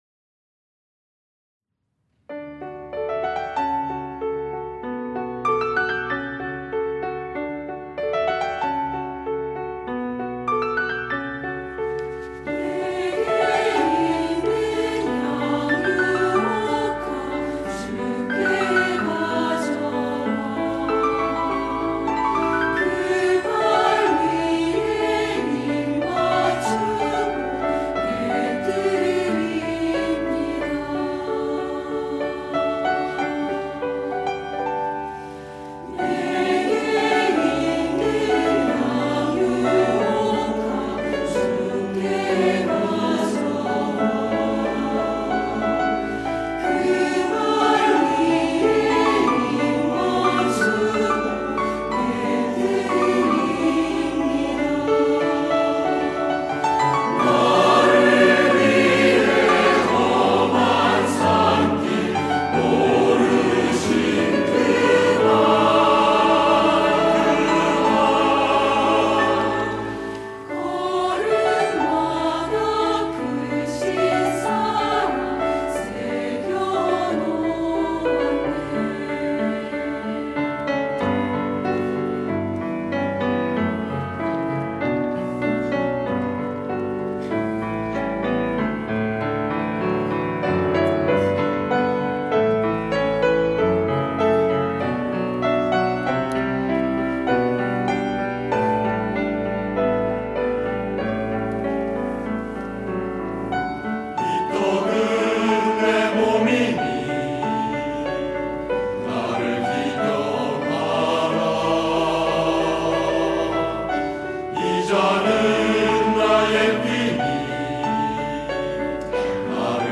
할렐루야(주일2부) - 내게 있는
찬양대